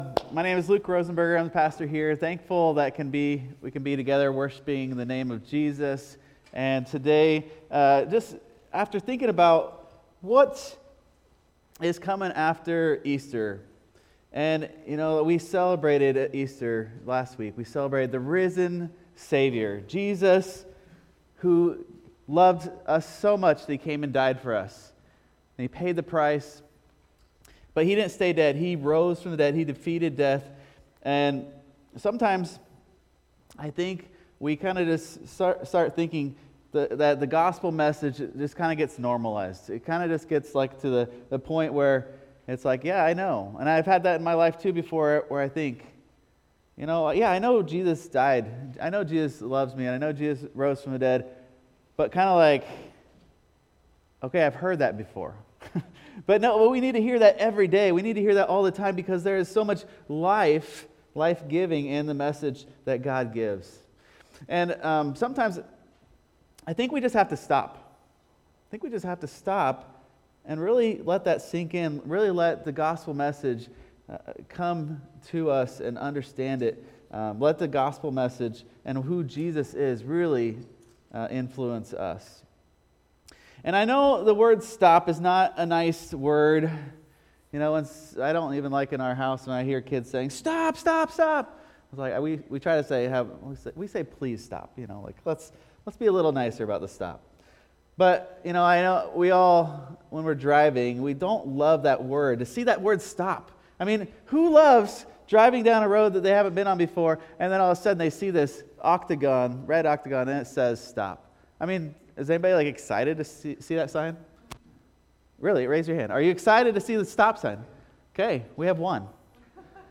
… continue reading 296 Episoden # Religion # Sunday Service # East Bend Mennonite Church # Christianity